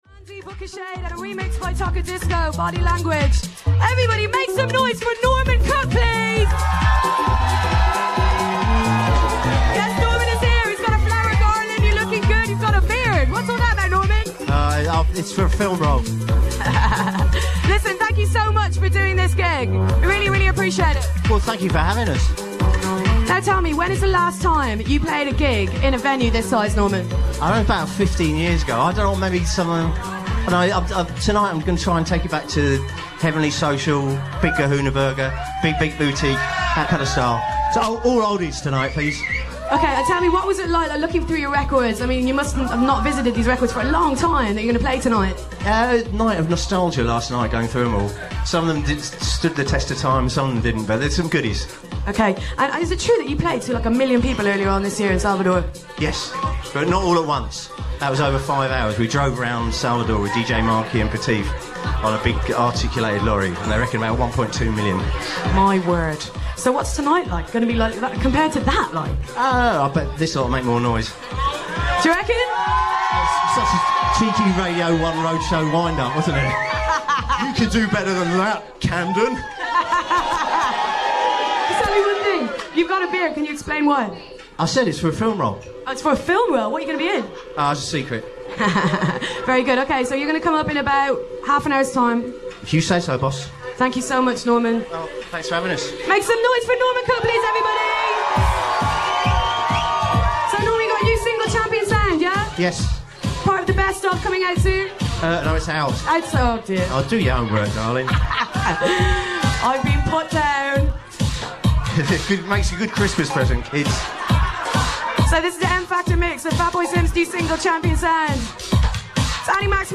Short interview with Annie Mac